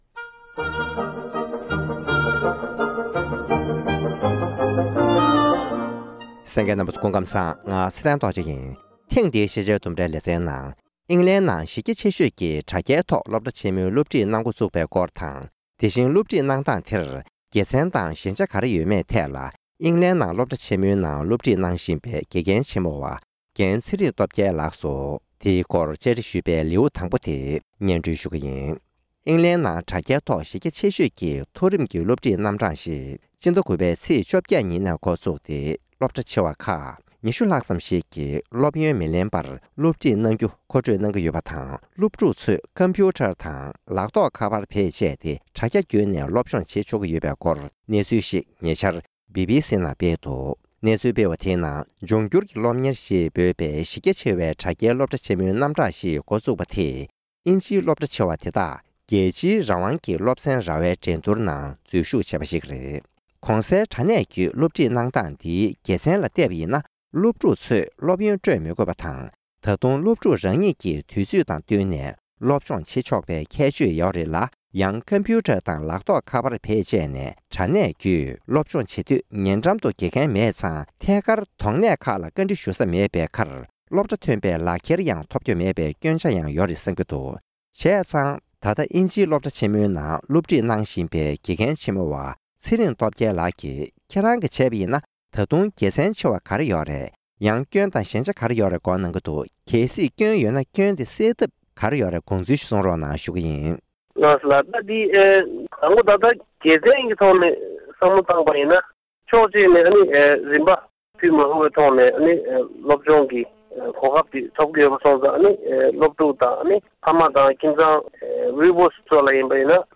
བཅར་འདྲི